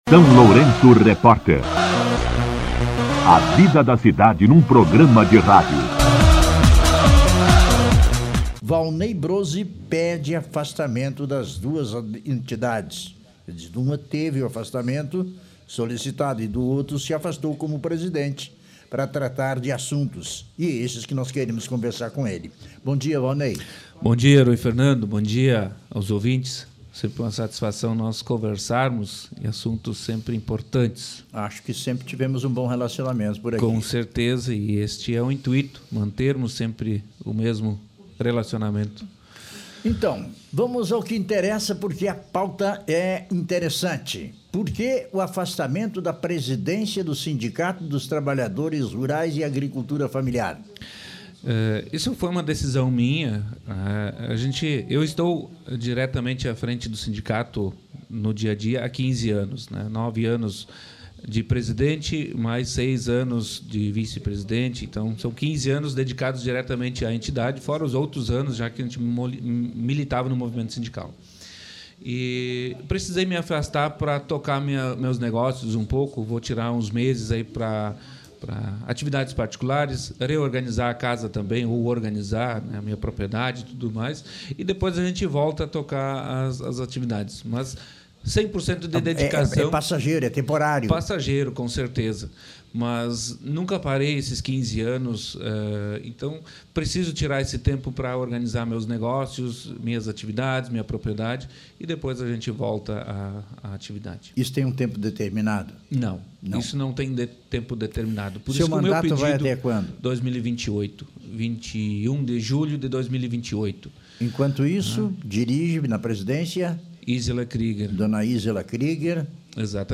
Durante entrevista ao SLR RÁDIO,